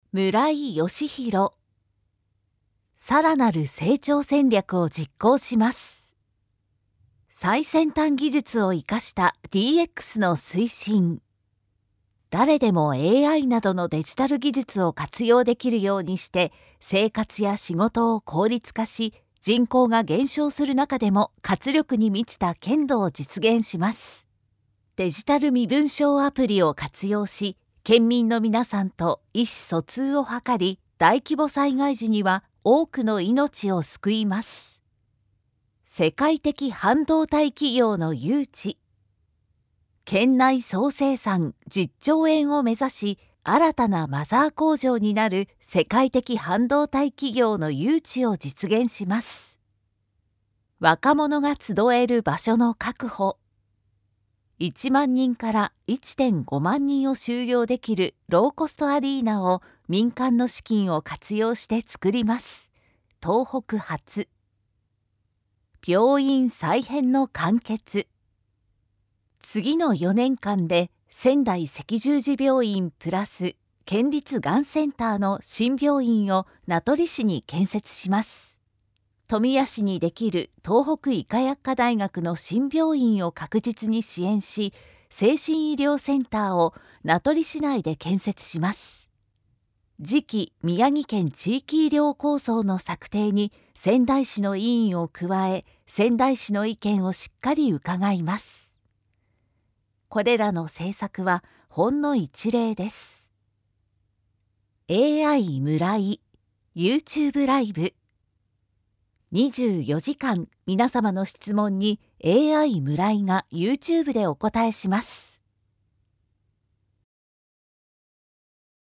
宮城県知事選挙候補者情報（選挙公報）（音声読み上げ用）